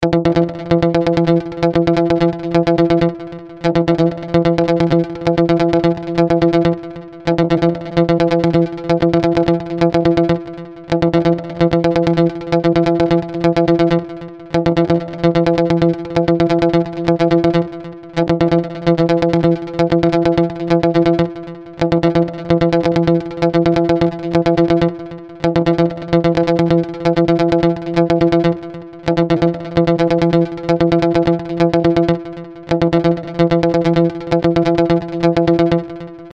シンプルでありながらも目を引く効果音です。
大事なメッセージや通知が届いたときに、この呼出音が鳴ることで注意を喚起し、見逃すことなく確認することができます。